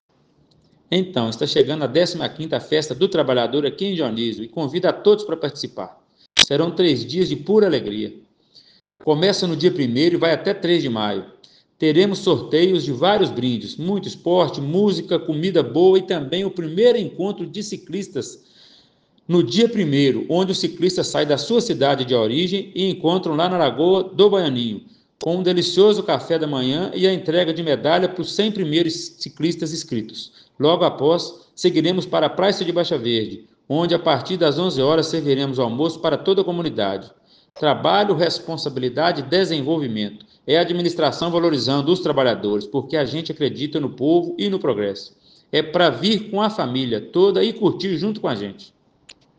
O prefeito Marciny Martins Pereira comentou sobre o evento e aproveitou para convidar a todos a estarem presentes em Dionísio nesses dias…
Audio-Marciny-Pereira-Prefeito-de-Dionisio.mp3